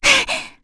Frey-Vox_Attack2.wav